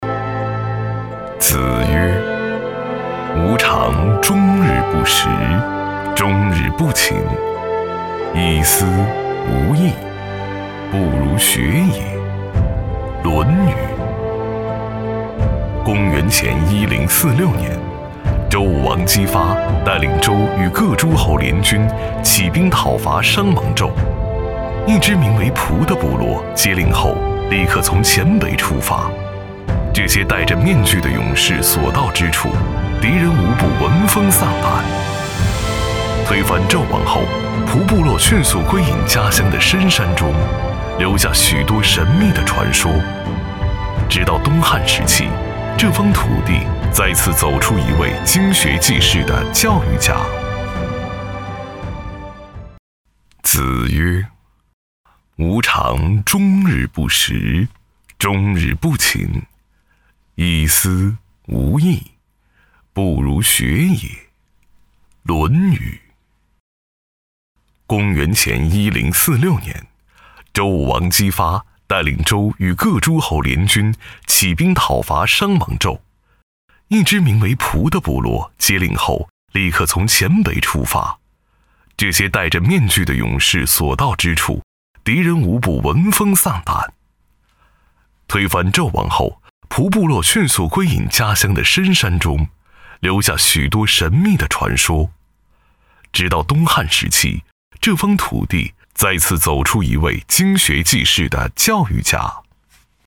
207男-政府形象-海西先行区
毕业于中国传媒大学播音主持专业，从事配音行业数年，普通话一级甲等水平，全能型风格加之高端的品质，让他的声音得到观众和业内的认可。
擅长：专题片 广告
特点：大气浑厚 稳重磁性 激情力度 成熟厚重
风格:浑厚配音